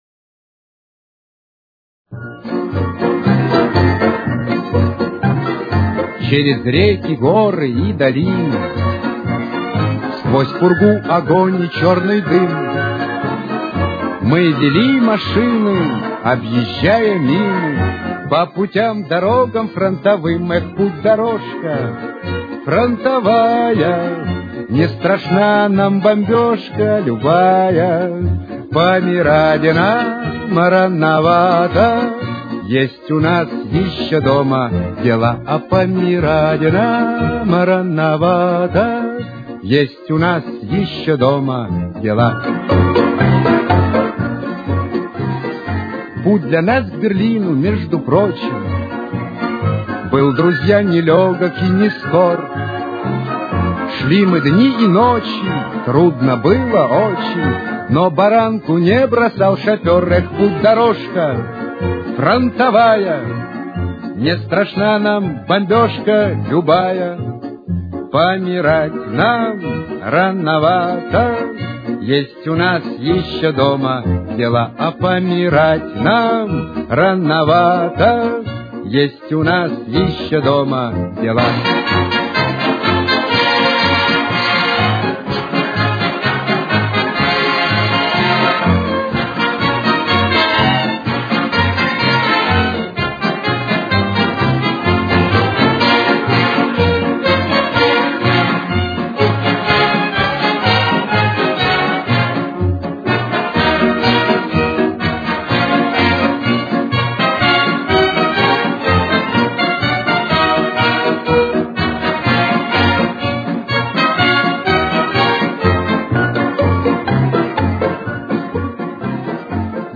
Си-бемоль минор. Темп: 124.